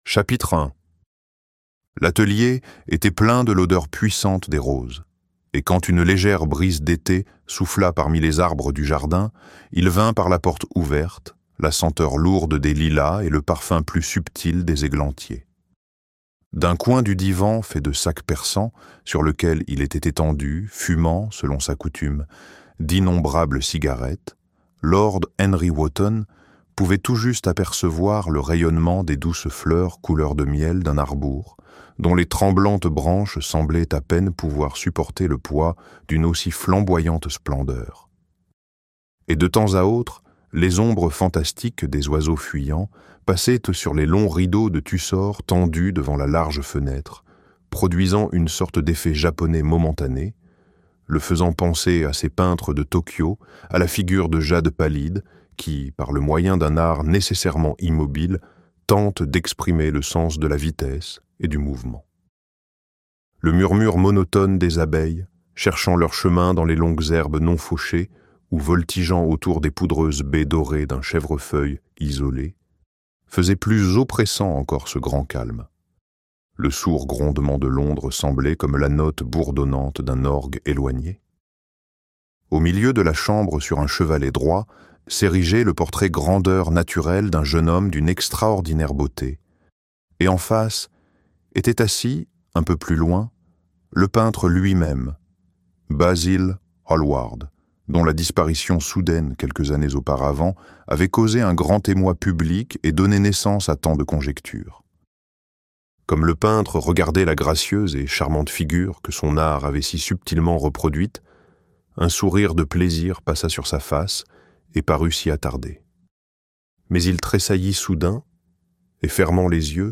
Le portrait de Dorian Gray - Livre Audio